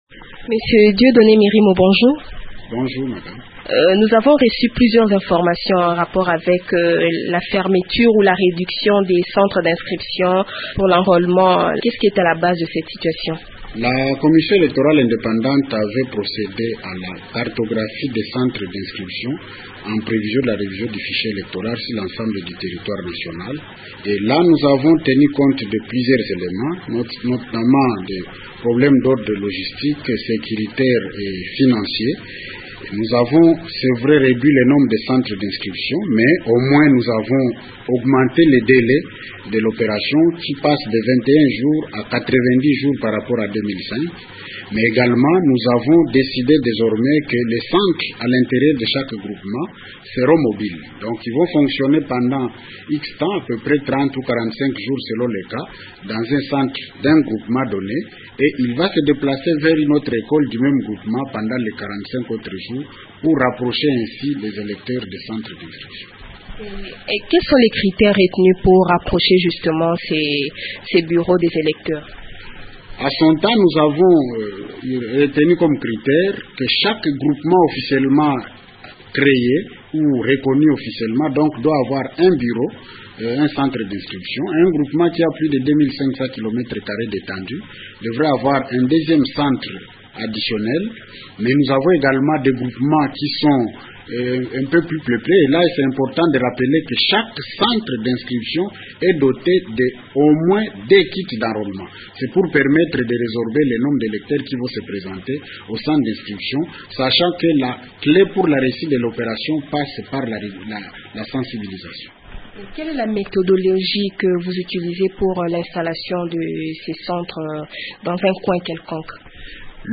Le rapporteur de la CEI, Dieudonné Mirimo répond aux questions